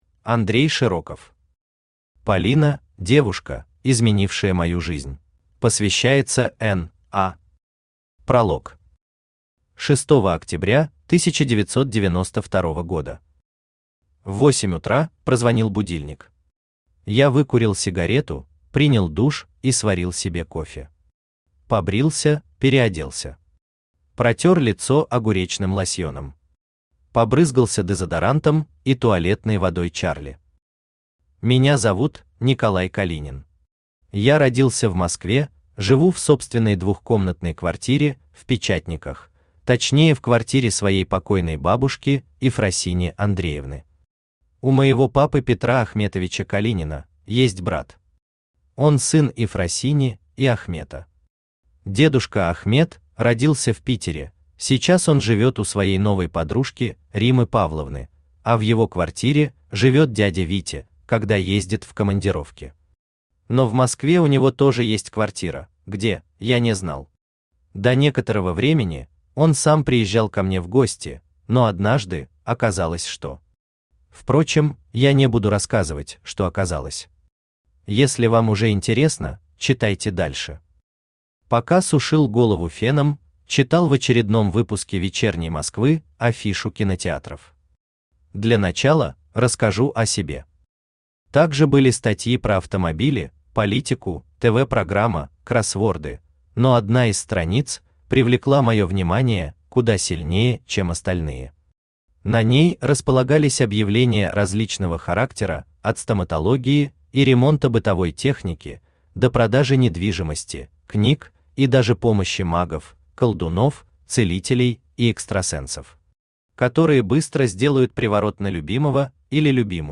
Аудиокнига Полина – девушка, изменившая мою жизнь | Библиотека аудиокниг
Aудиокнига Полина – девушка, изменившая мою жизнь Автор Андрей Широков Читает аудиокнигу Авточтец ЛитРес.